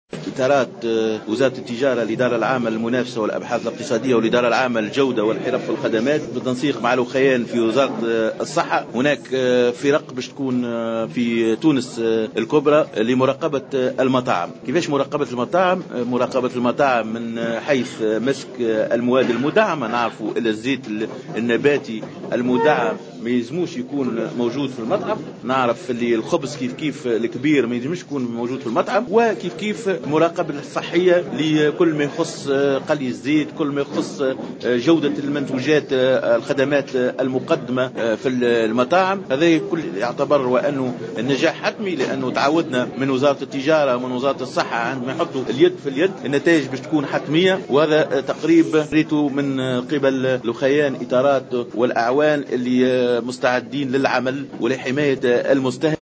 أكد وزير التجارة رضا لحول في تصريح اعلامي على هامش إشرافه على انطلاق حملة مراقبة...